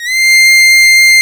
STRS C6 F.wav